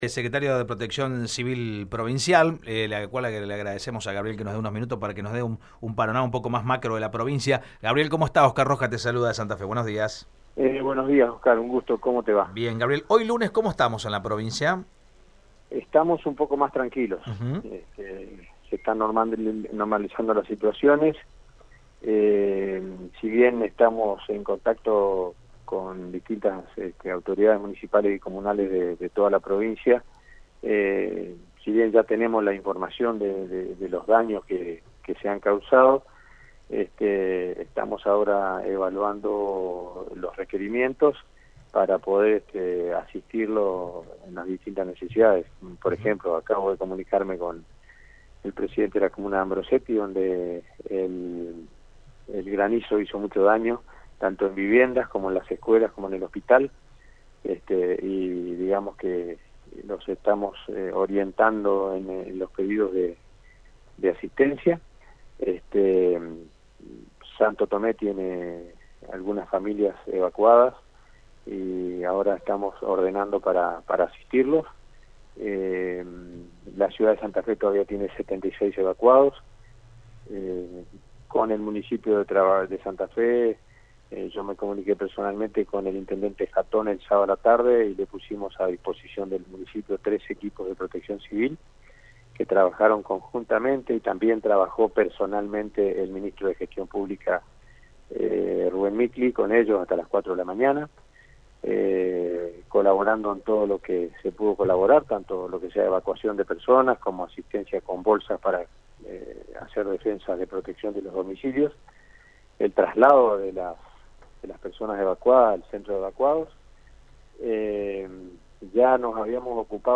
Gabriel Gasparutti, secretario de Protección Civil de la provincia, definió que hoy «estamos mas tranquilos» aunque siguen las comunicaciones con los intendentes y presidentes comunales de Santa Fe.
Gabriel-Gasparutti-secretario-de-Protección-Civil-de-la-Provincia-de-Santa-Fe.mp3